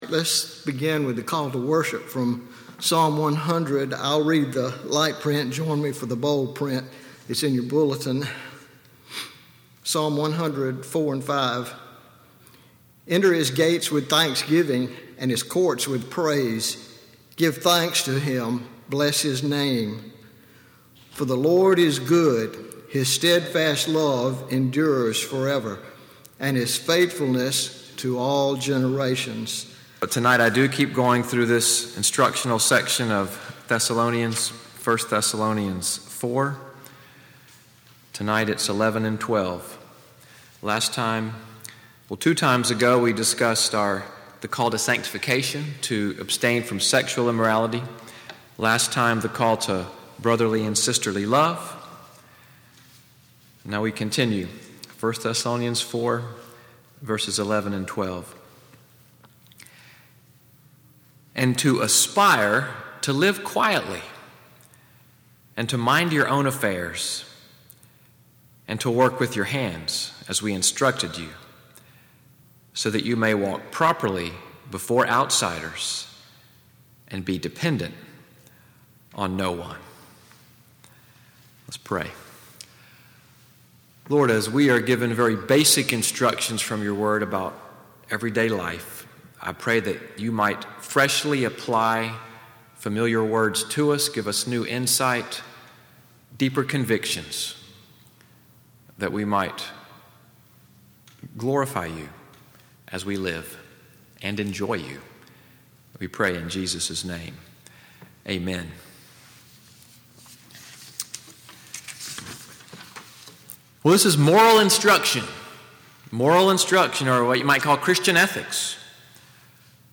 SUNDAY EVENING WORSHIP at NCPC-Selma, audio from the sermon, “Everyday Life & Conduct,” Preached December 3, 2017.